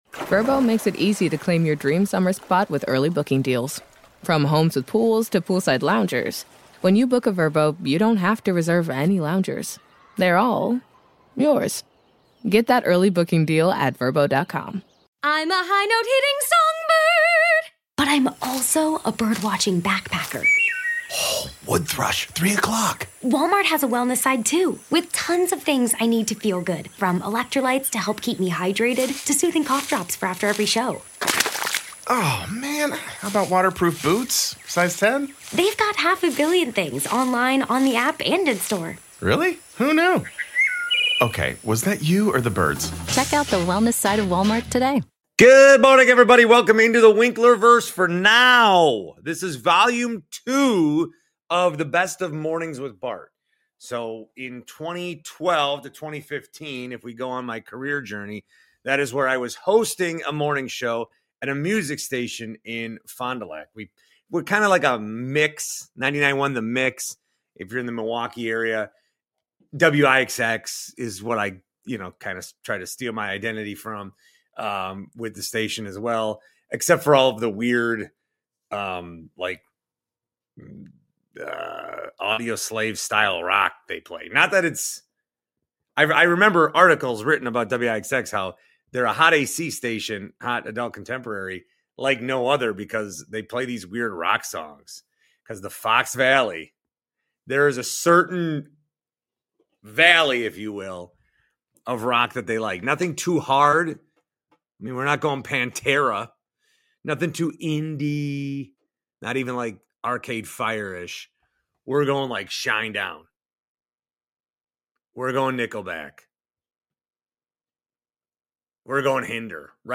Rocket Fizz soda taste test and interview, interview with singer Joan Osborne